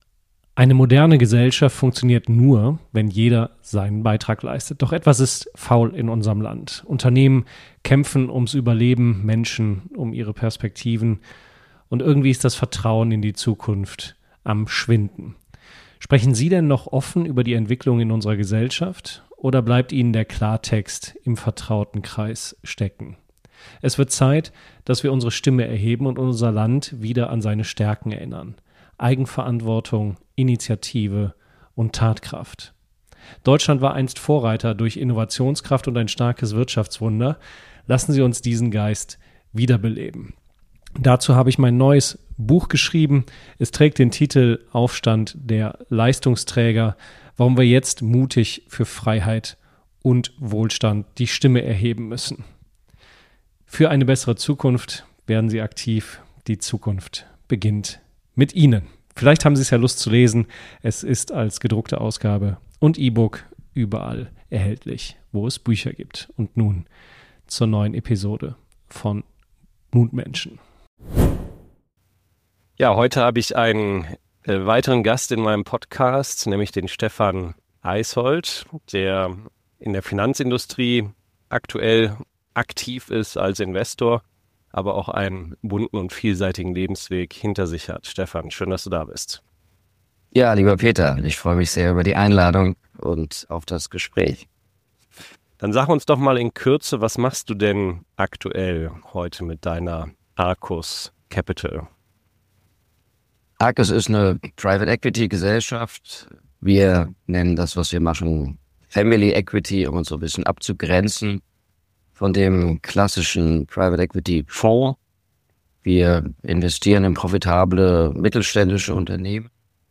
Und warum sind starke Beziehungen wichtiger als reine Zahlen? Ein Gespräch über Erfolg, Krisen und den Mut, neue Wege zu gehen.